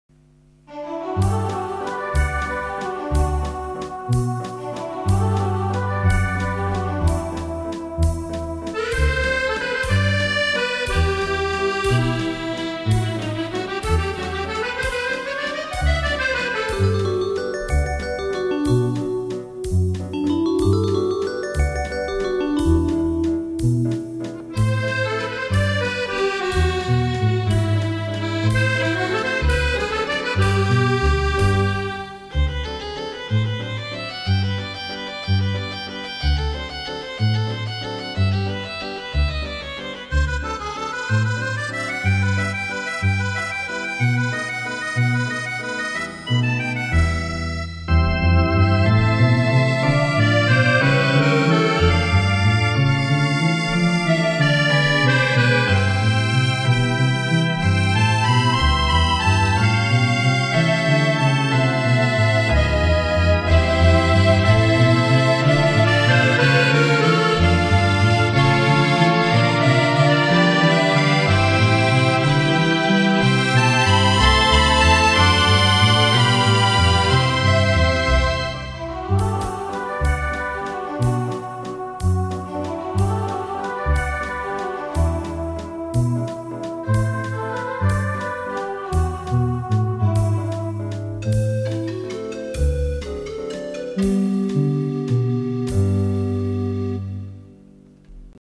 In all the demo files, the Midi Partner was recorded in one session directly (no multi tracking) with no other treatments just as in a live performance. Note how the drums follow the exact tempo as you slow down and speed up. There is the live accordion mixed in just as you would hear it.